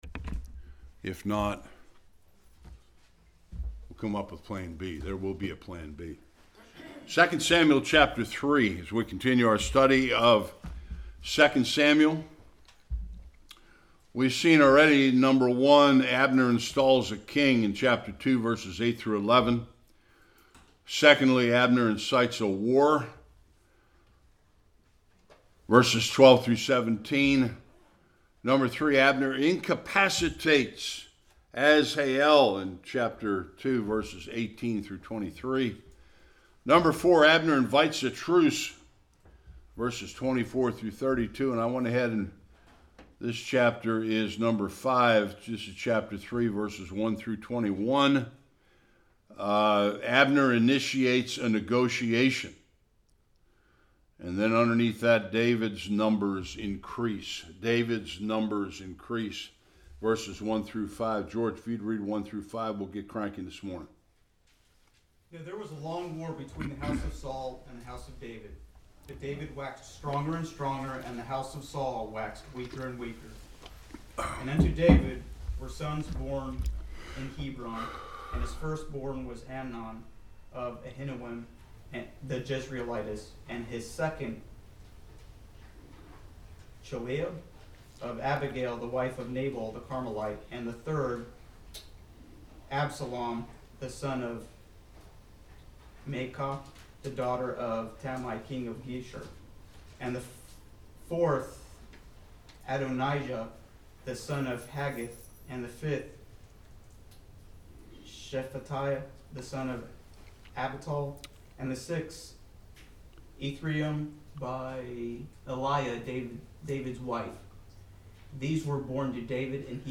1-21 Service Type: Sunday School After making a covenant with David to help consolidate the kingdom